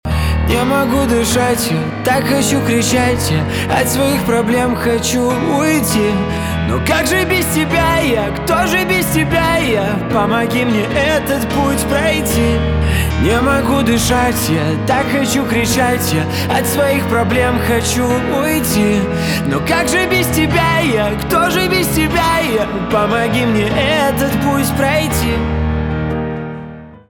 поп
грустные , печальные , депрессивные , пианино , чувственные